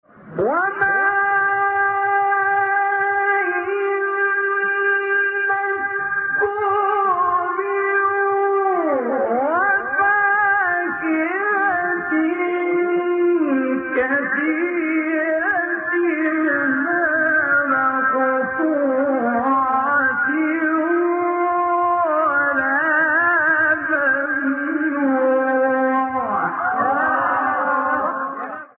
تلاوت
مقام : بیات